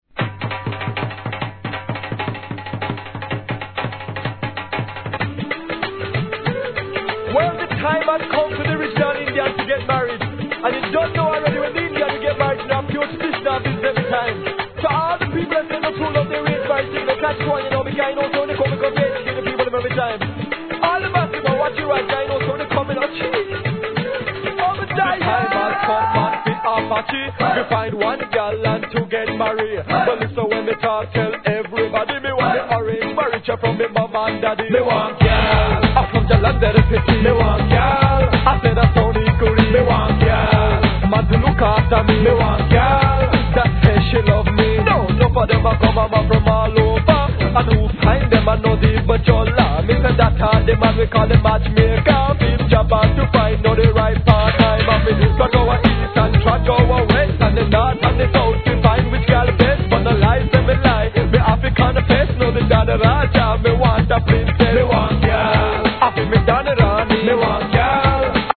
1. REGGAE
インド/バングラ要素を取り入れた内容はあのPANJABI MCを彷彿させます。